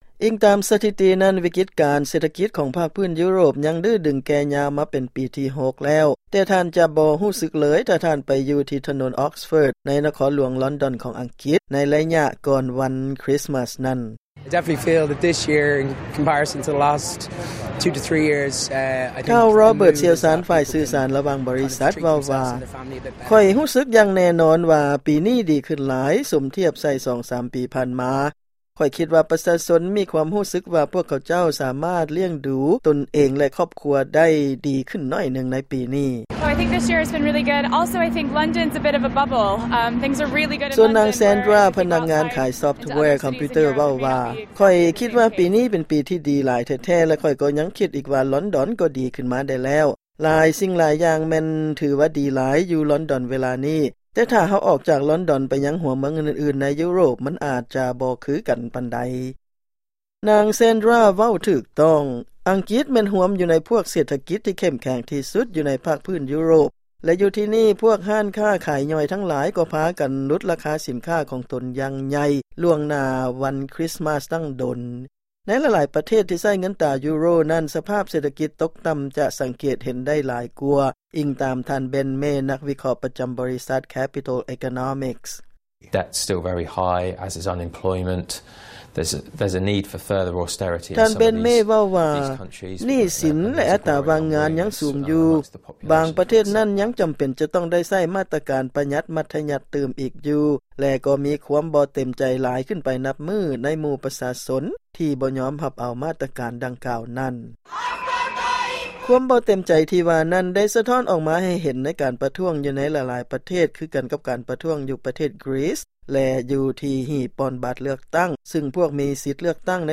ຟັງລາຍງານສະຫລູບທ້າຍປີ ກ່ຽວກັບເສດຖະກິດຂອງຢູໂຣບ